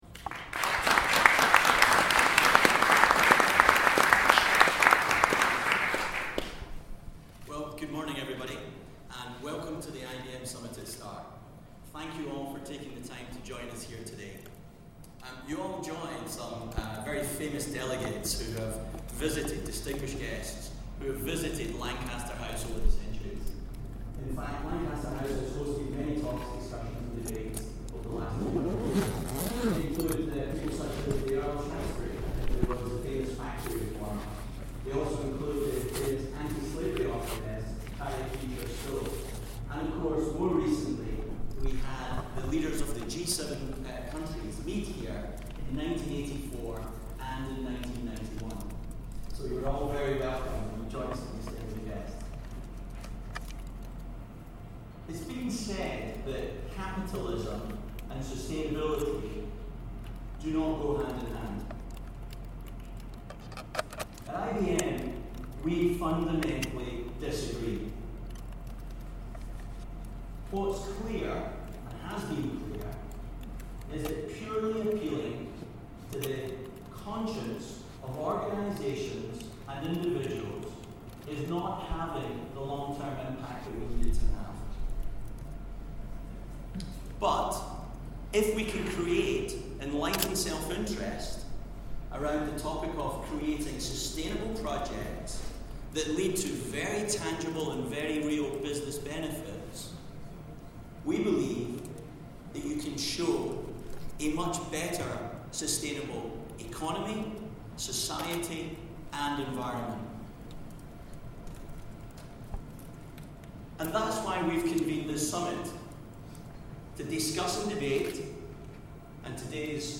IBM Start Day 1 - Welcome Address